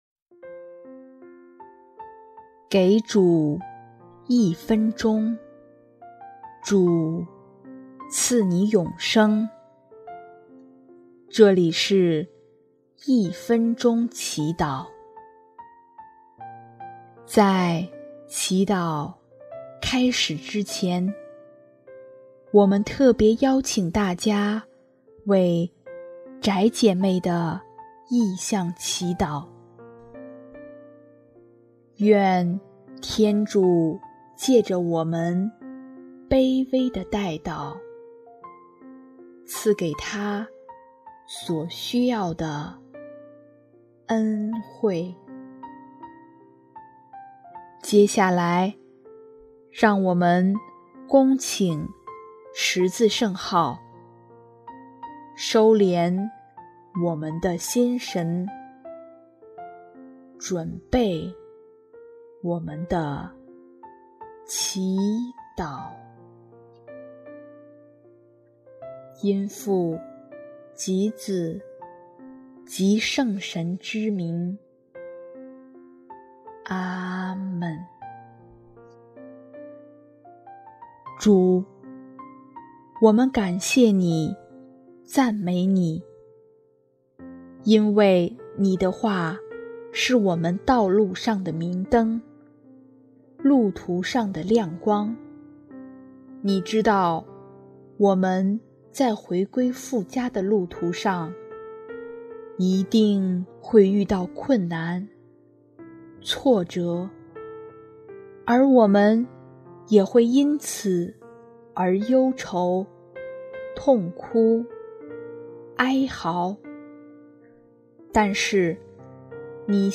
音乐 ：第三届华语圣歌大赛参赛歌曲《不朽的爱》